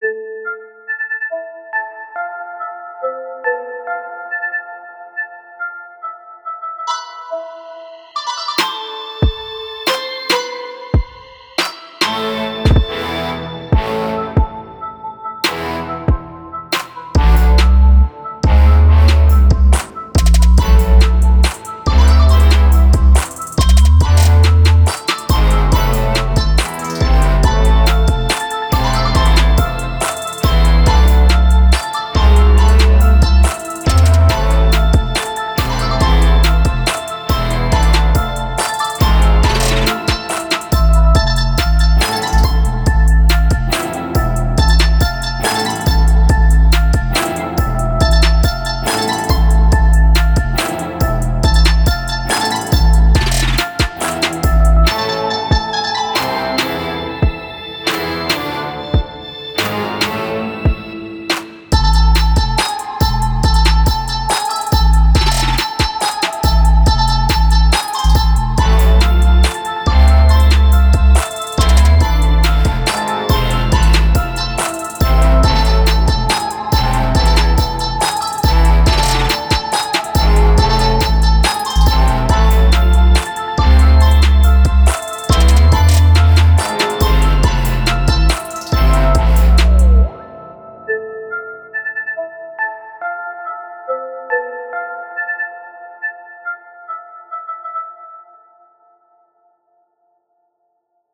Еще не... Dubstep. Future Bass
Синхронизации не хватает. Тембры уже получше. Панораму ровнее бы, а то звуки плавают.
Оба варианта баса в дорожке присутствуют.